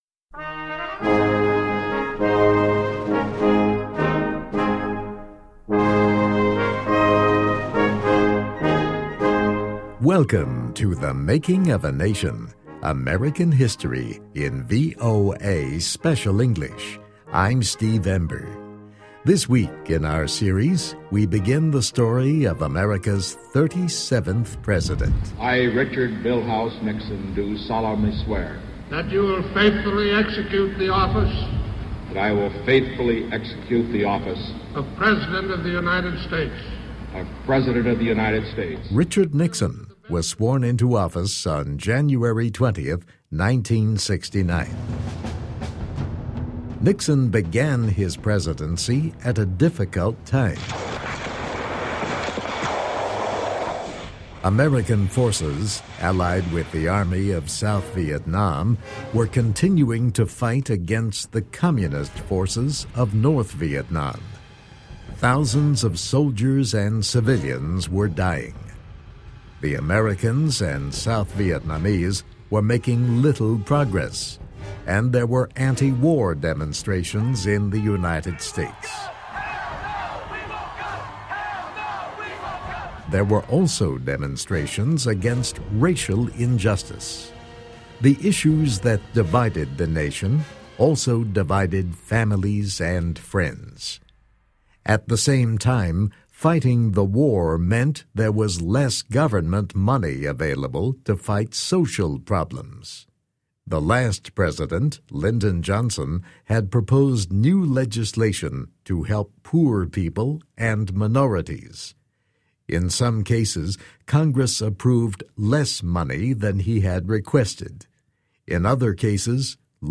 (Viet Nam War battle sounds) American forces, allied with the Army of South Vietnam, were continuing to fight against the communist forces of North Vietnam.